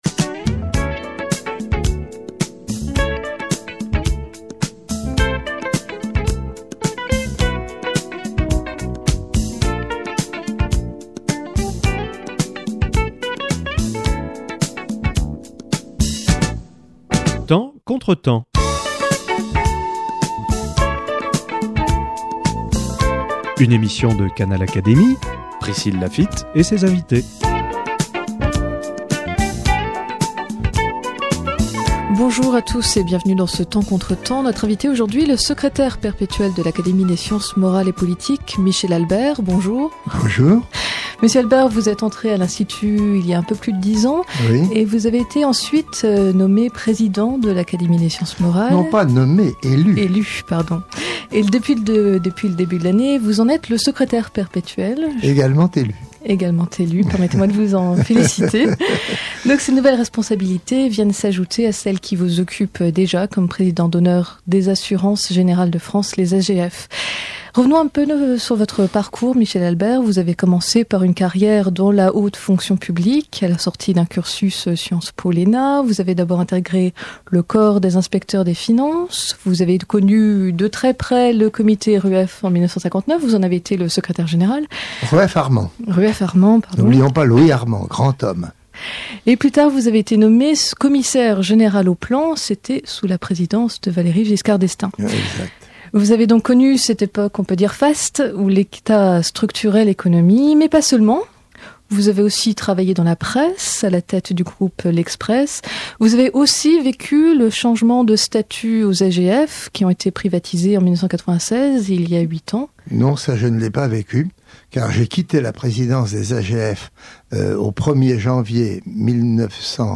Rencontre avec Michel Albert - acteur économique
La façon qu’ont les politiques de faire de l’économie a-t-elle changé depuis 50 ans ? Un Académicien et deux étudiants confrontent leurs analyses, leur perception du débat au regard de l’actualité.